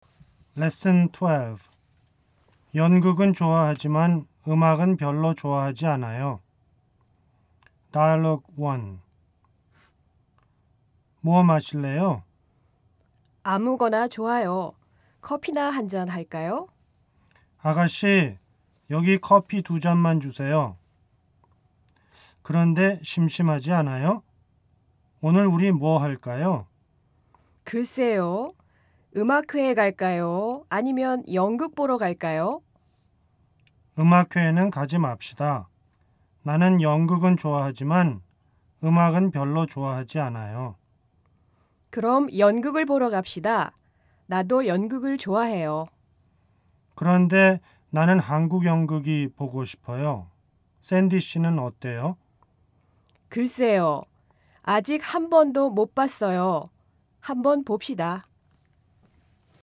Lesson 12 Dialogue 1.mp3